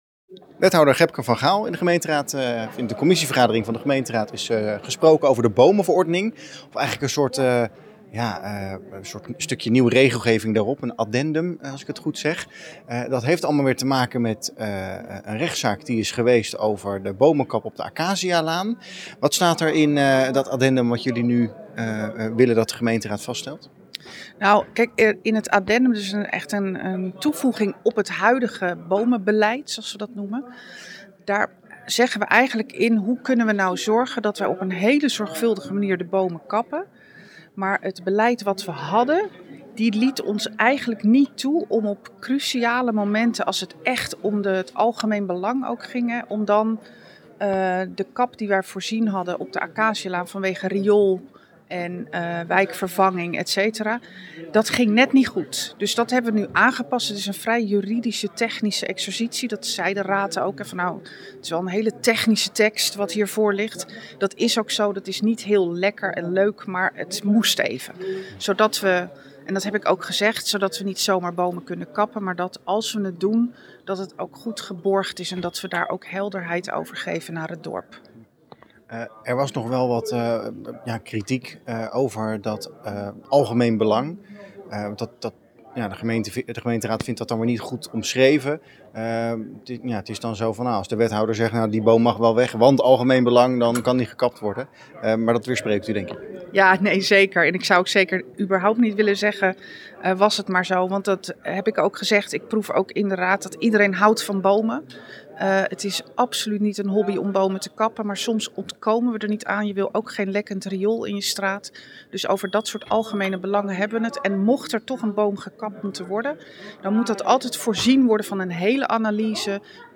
in gesprek met wethouder Gebke van Gaal over het aangepaste bomenbeleidsplan.
Gepke-van-Gaal-over-bomen-verordening.mp3